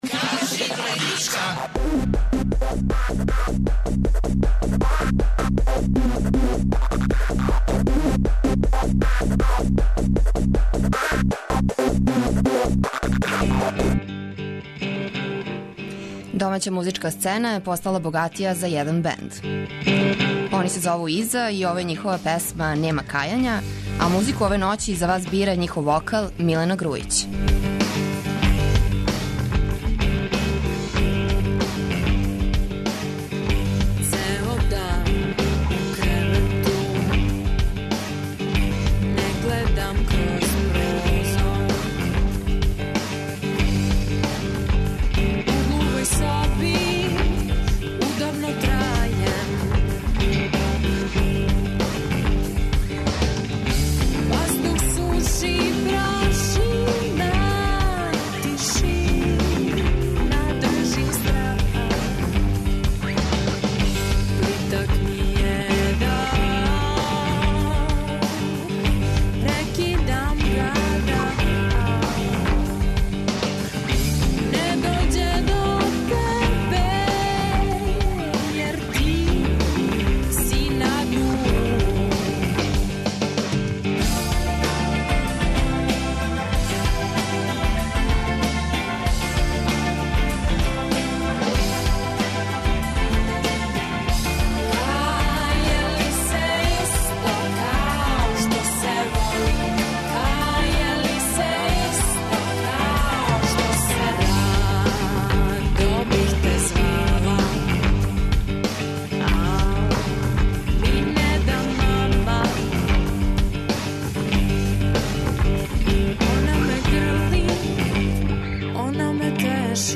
Поред песама са њиховог новог албума, у вечерашњој Драгички слушамо и оне које вам они препоручују.